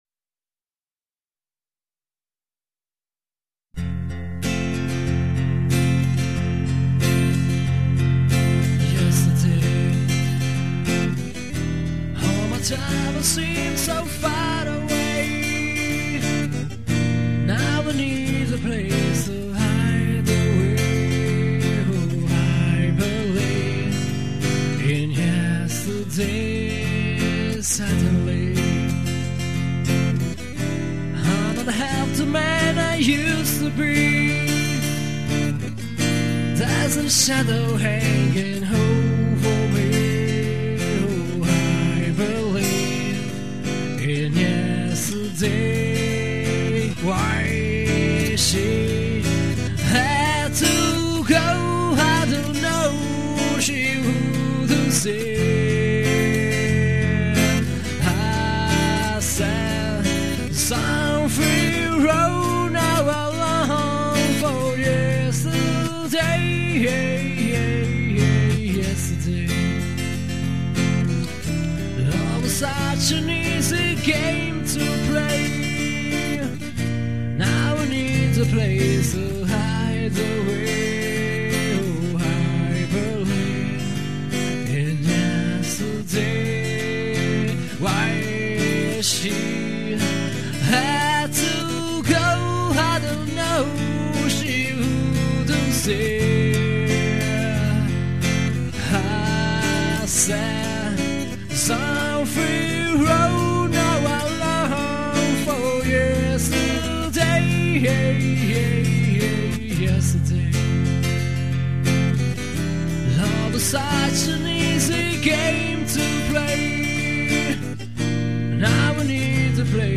La canzone � stata registrata come unica traccia
chitarra elettroacustica